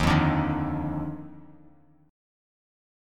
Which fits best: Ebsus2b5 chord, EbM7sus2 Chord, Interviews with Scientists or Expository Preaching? EbM7sus2 Chord